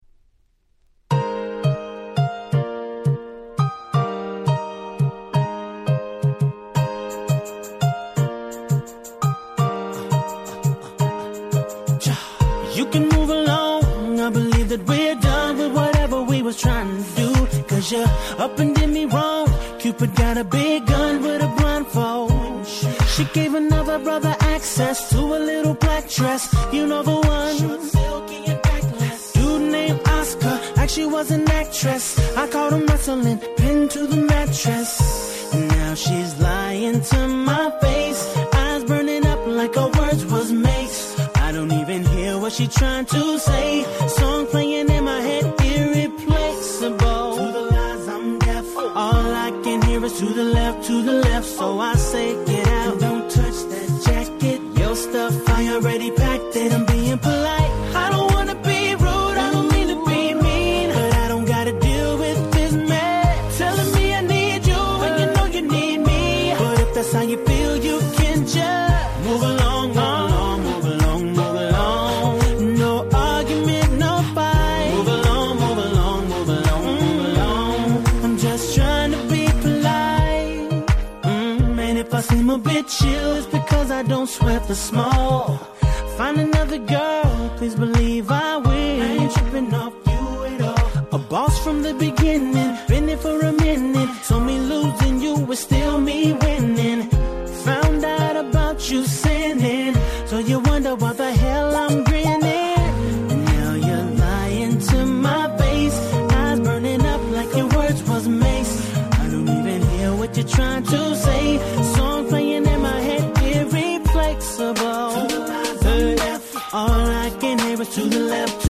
10' Nice R&B !!